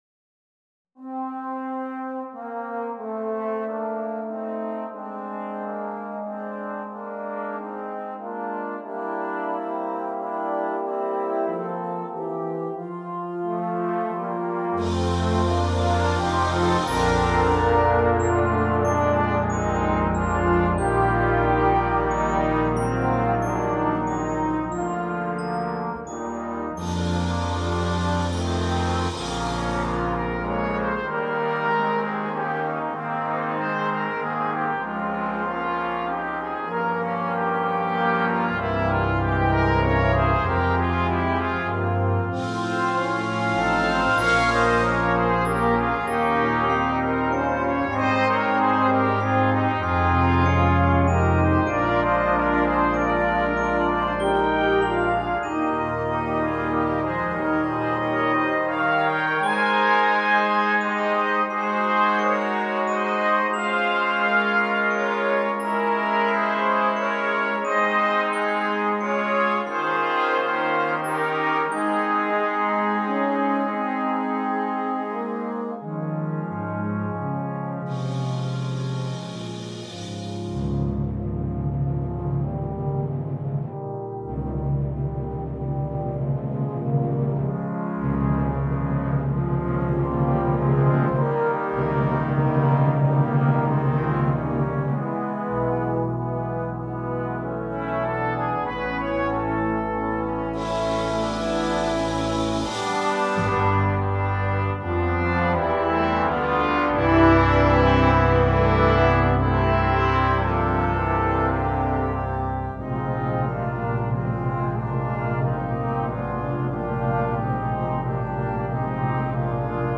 (Chorus Arrangement)